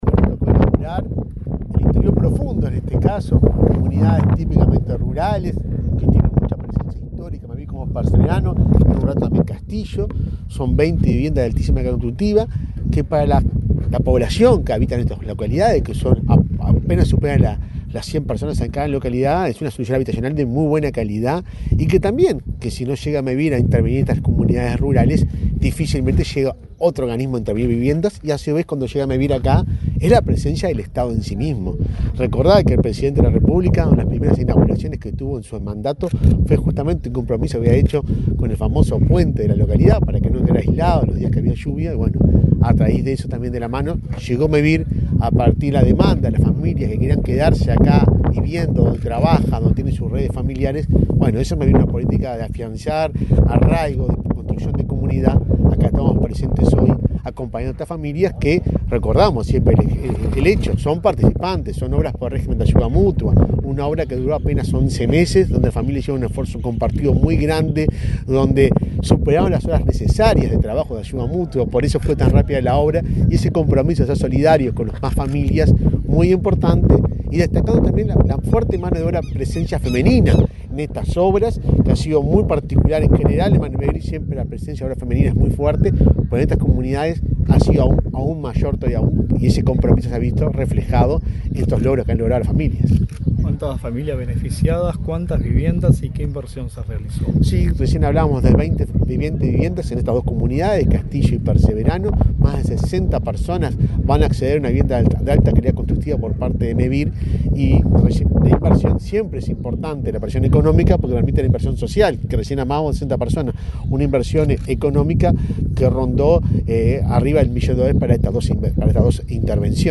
Entrevista al presidente de Mevir, Juan Pablo Delgado
El presidente de Mevir, Juan Pablo Delgado, dialogó con Comunicación Presidencial, antes de participar en la inauguración de viviendas nucleadas en la